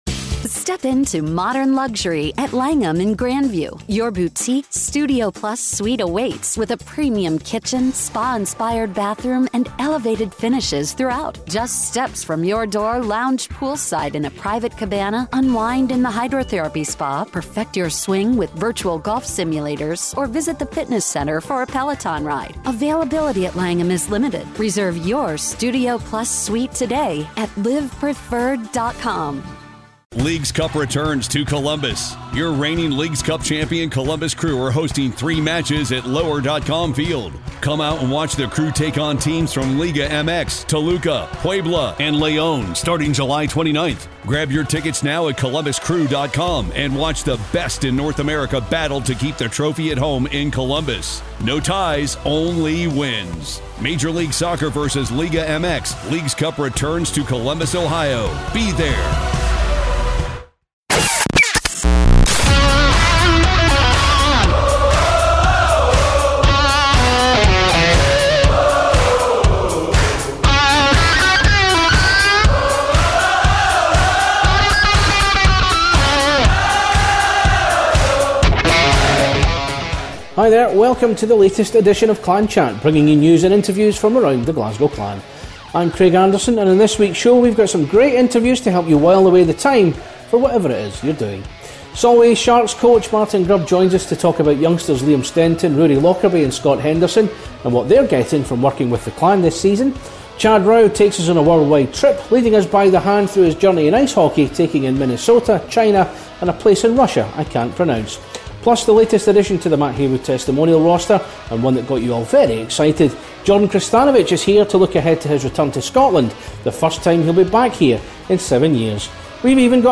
It's another packed edition of Clan Chat, with the latest interviews from around Glasgow Clan.